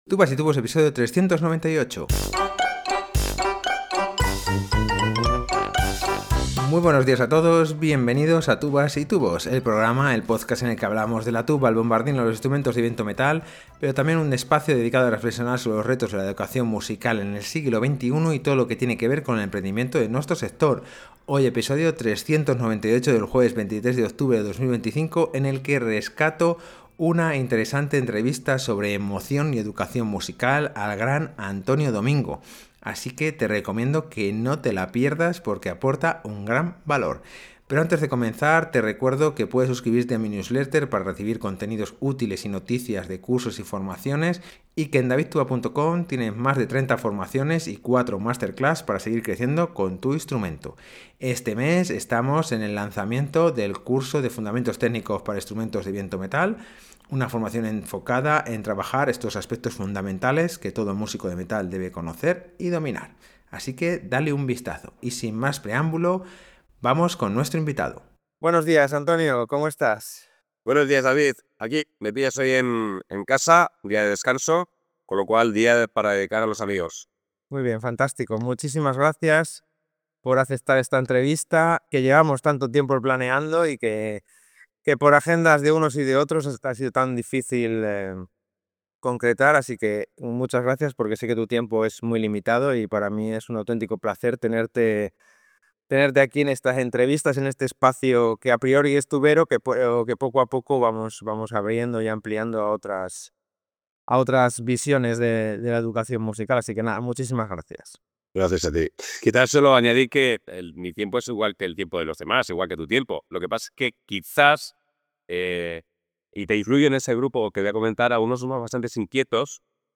En este episodio rescatamos una interesante entrevista al formador de docentes